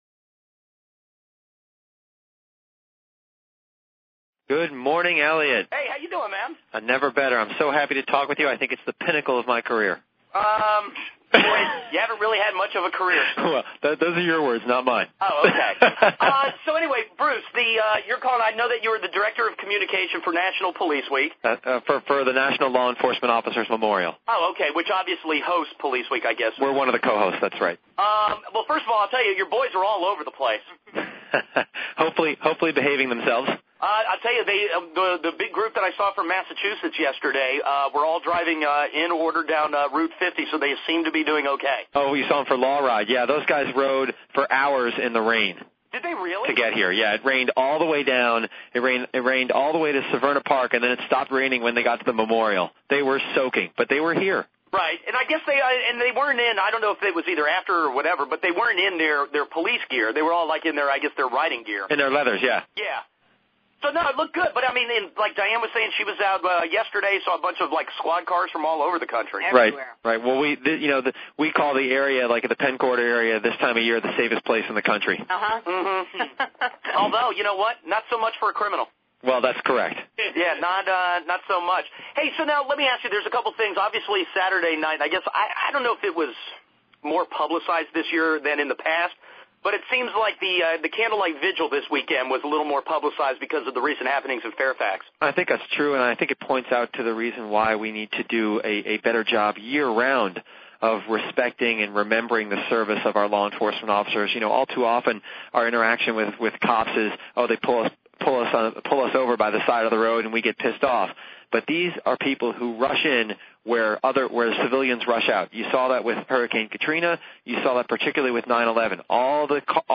WWDC-FM, Washington, DC, National Police Week Interview (Client: NLEOMF)